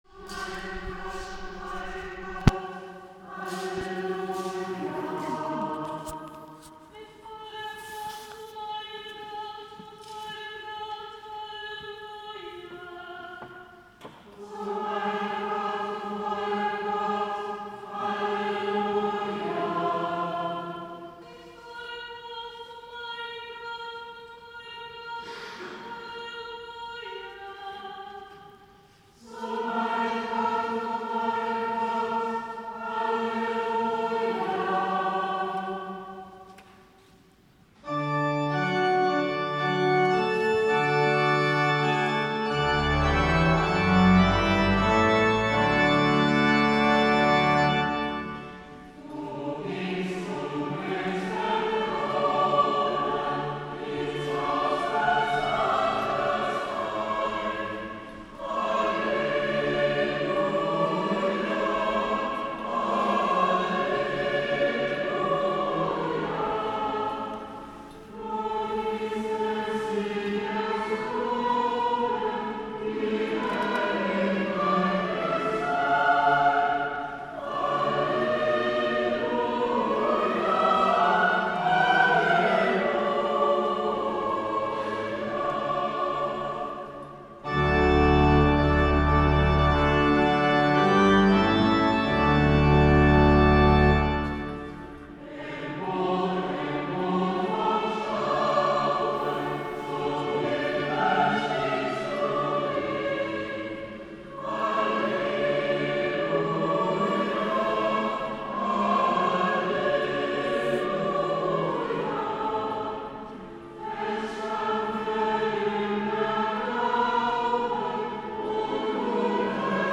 Video Christi Himmelfahrt 2025
Himmelfahrtslied 2019 mit Chirchenchor